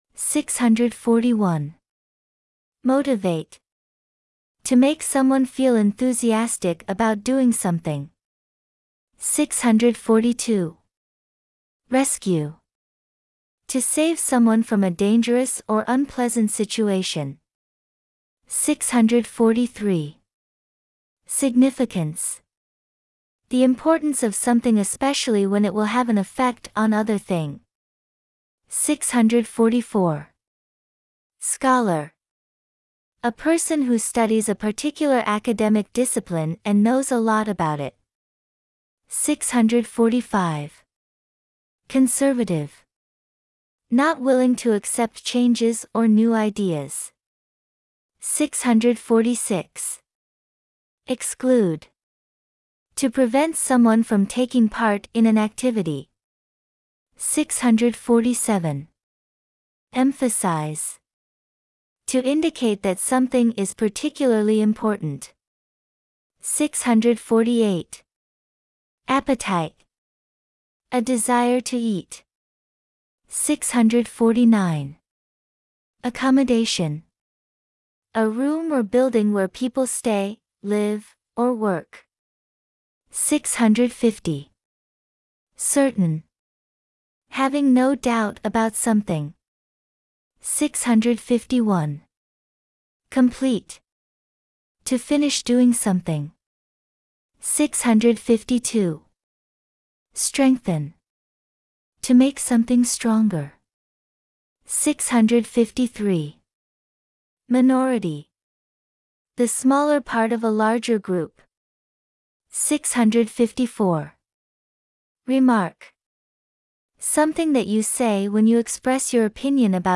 電子書籍 共通テスト英語・英英超速楽習単熟語 に収録された８２０語を単語・英英定義・例文の順でネイティブ音声が読み上げるyoutube 【共通テスト英語キーワード820】共通テスト英語キーワード820単語・英英定義・例文音声 を耳で聴くだけで訳語をすべて正しく答えられるようになった受験生は、共通テスト英語過去問で８割以上得点できています。
以下に紹介するのは共通テスト英語キーワードカノンの(641)~(820)の再々復習準備と再々復習で、この部分は重複なしのためハイペースで習得を確認できます。 69日目(641)~(820)再々復習準備 ※ (641)~(820)英英定義音声 に合う訳語を選んで空欄に記入してください。